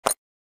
جلوه های صوتی
برچسب: دانلود آهنگ های افکت صوتی اشیاء دانلود آلبوم صدای کلیک موس از افکت صوتی اشیاء